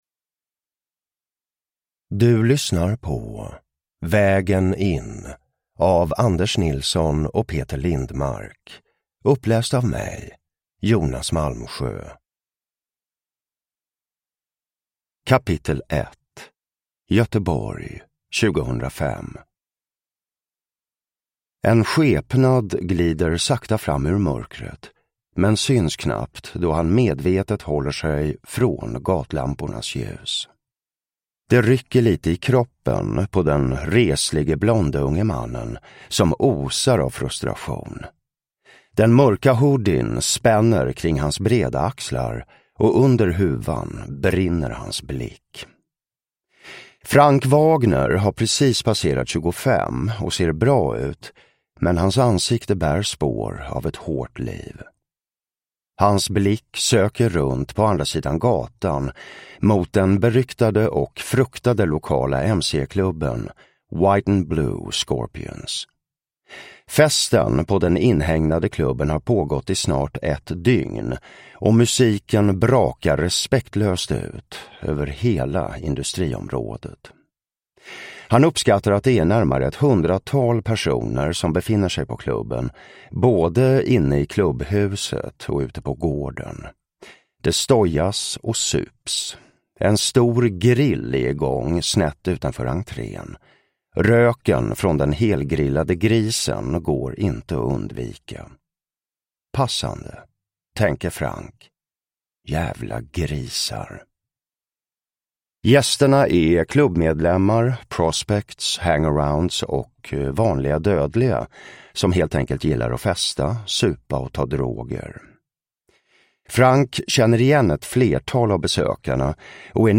Uppläsare: Jonas Malmsjö
Ljudbok